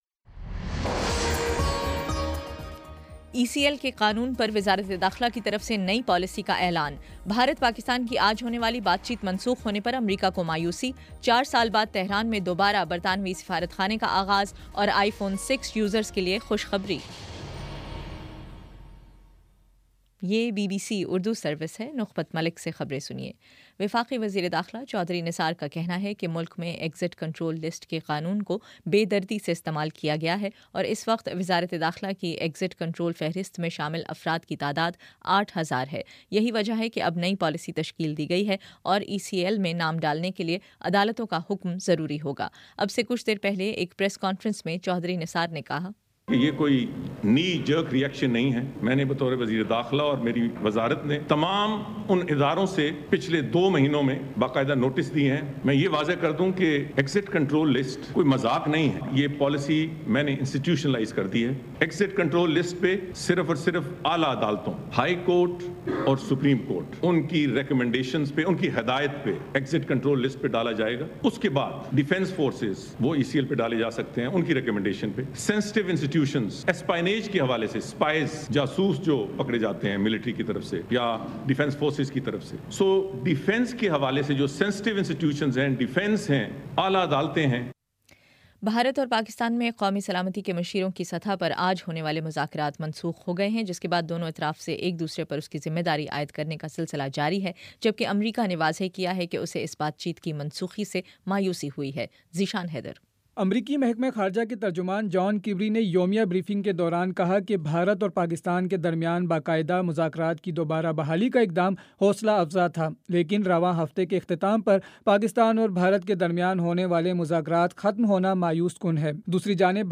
اگست 23: شام چھ بجے کا نیوز بُلیٹن